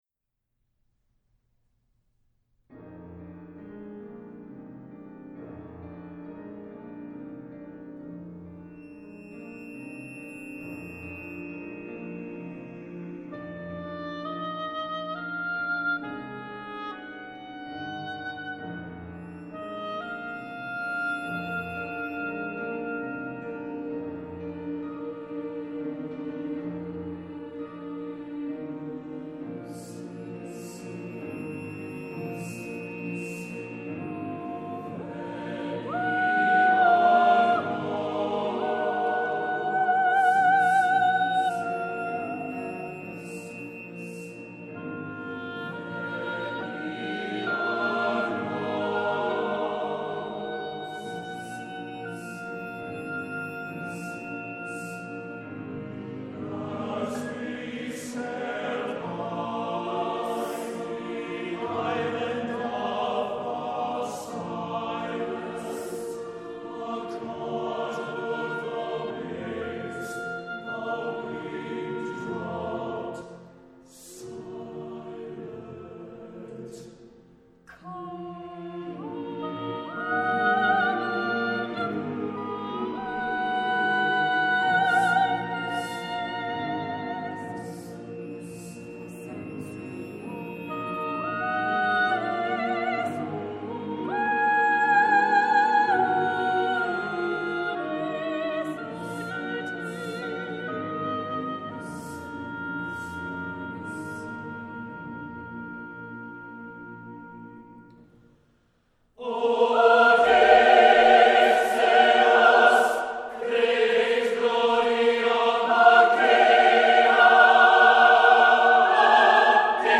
Voicing: SATB divisi and Piano